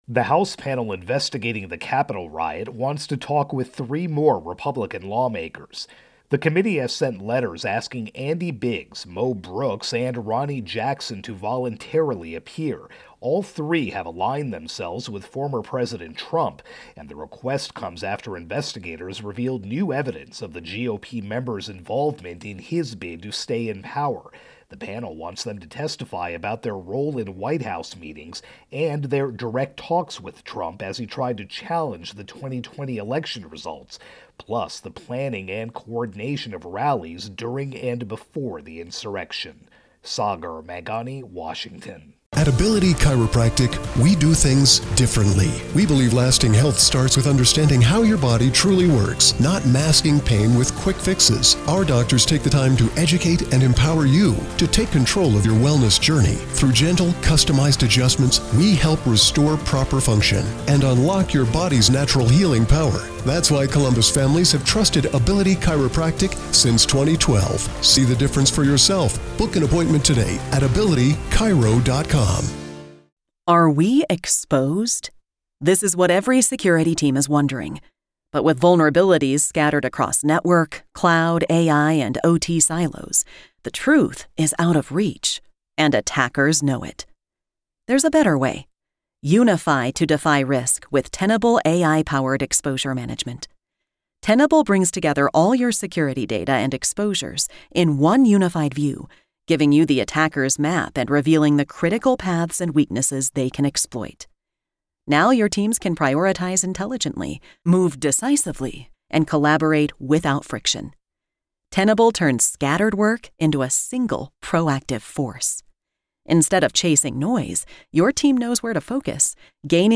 Capitol Riot-Investigation-Lawmakers intro and voicer.